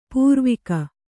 ♪ pūrvika